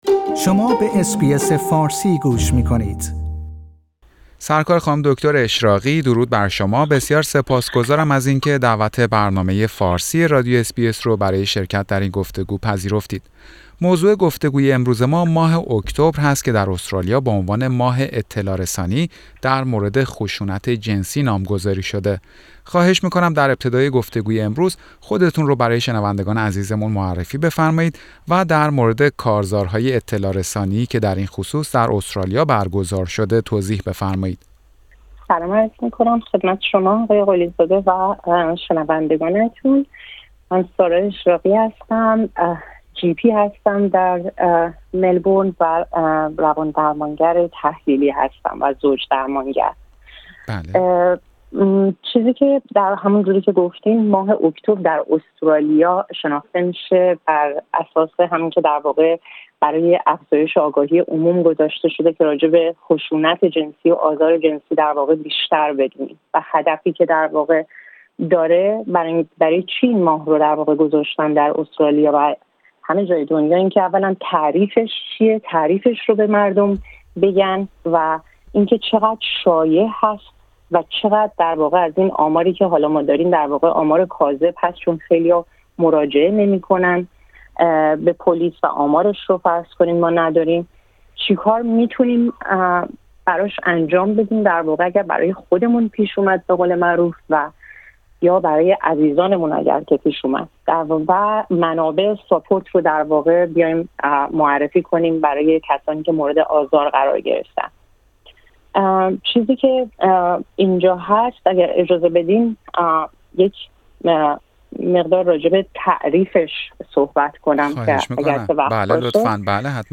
برنامه فارسی رادیو اس بی اس در همین خصوص گفتگوی داشته